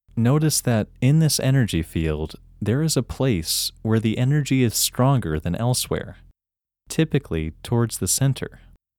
IN – First Way – English Male 4
IN-1-English-Male-4.mp3